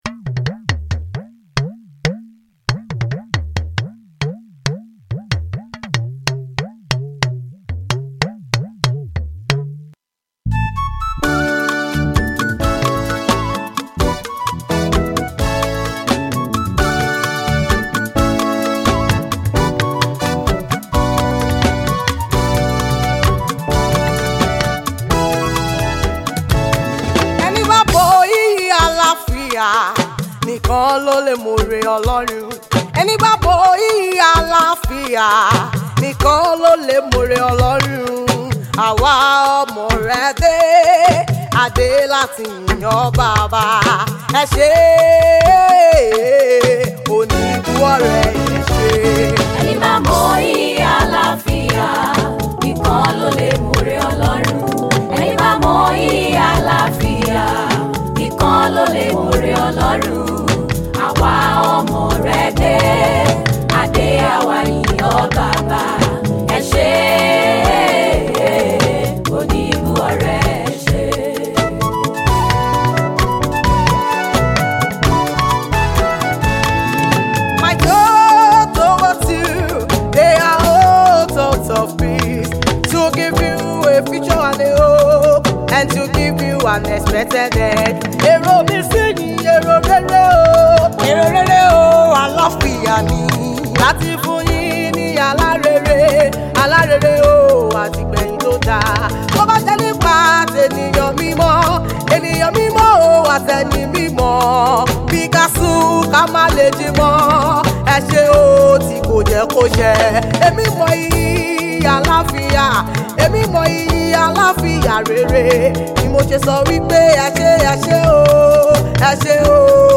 Versatile gospel musician and anointed song writer